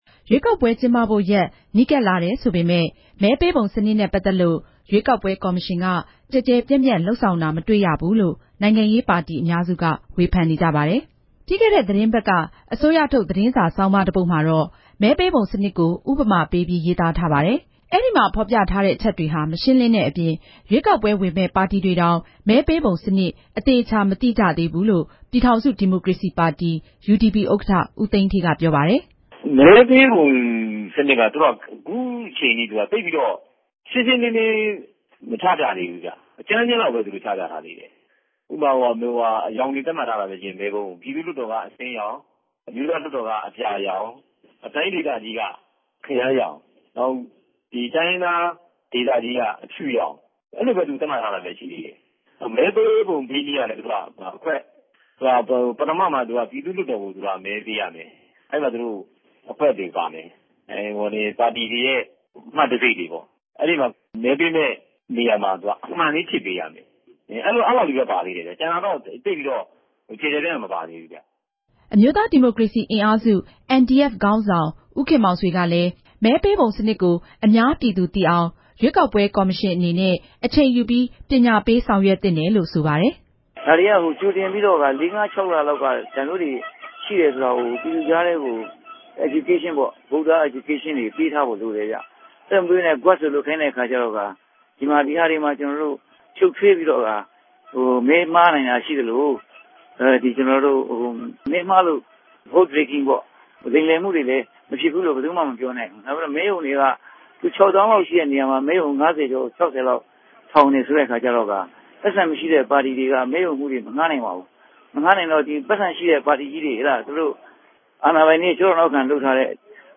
ဆက်သွယ်မေးမြန်းချက်။